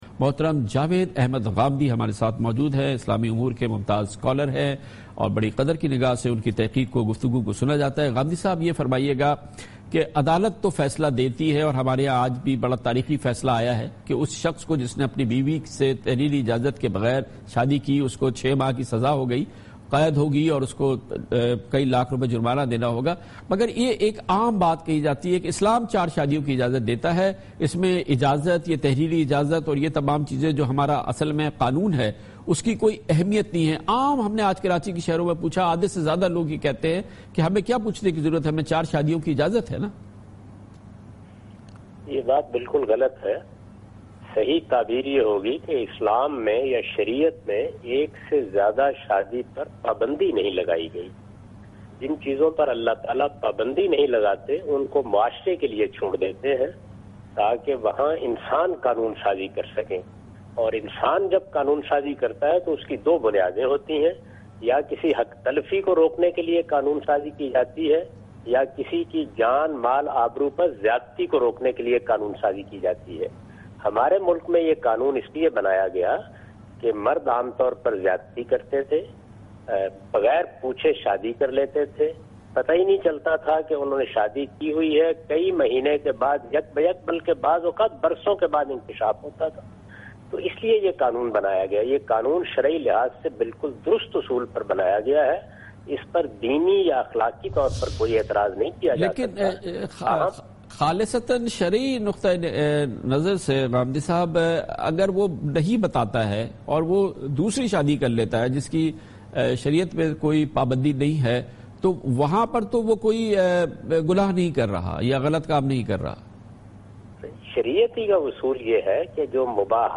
Javed Ahmad Ghamidi responds to the question 'Issue of Second Marriage' in program Dunya Kamran Khan Kay sath on Dunya News.
جاوید احمد غامدی دنیا نیوز کے پروگرام دنیا کامران کے ساتھ میں "اسلام میں دوسری شادی کا مسئلہ ؟"سے متعلق سوال کا جواب دے رہے ہیں۔